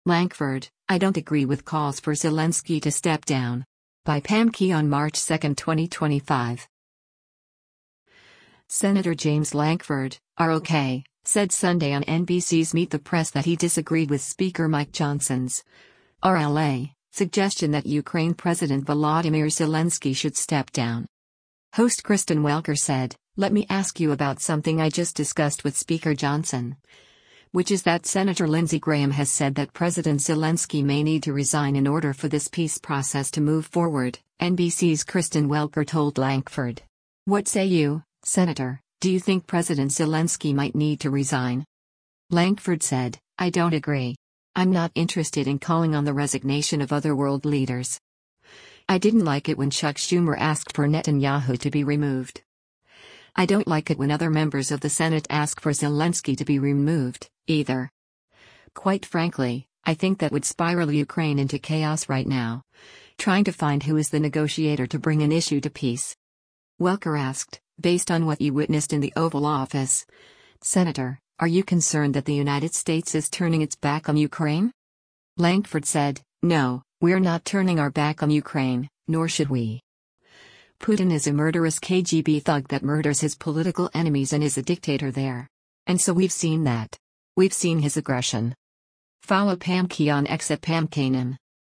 Senator James Lankford (R-OK) said Sunday on NBC’s “Meet the Press” that he disagreed with Speaker Mike Johnson’s (R-LA) suggestion that Ukraine President Volodymyr Zelensky should step down.